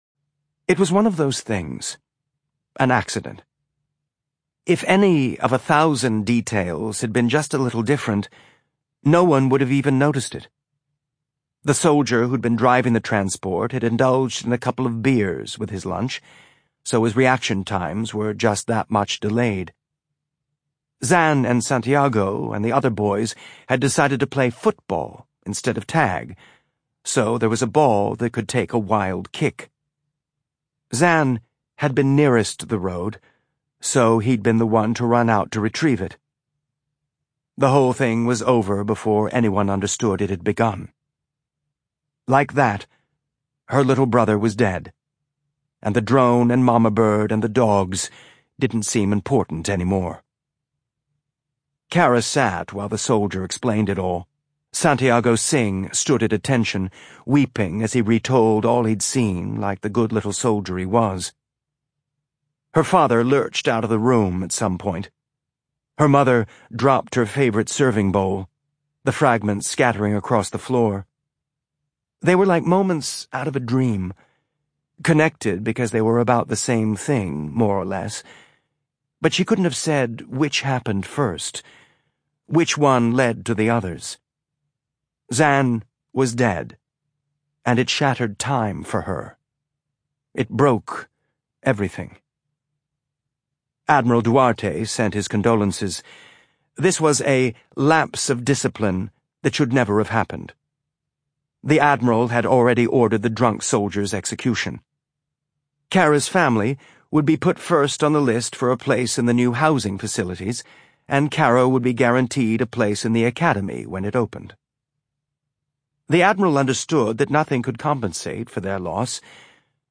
drop/books/audiobooks/James S. A. Corey - The Expanse (complete series)/narrated by Jefferson Mays/6.5 - Strange Dogs (novella)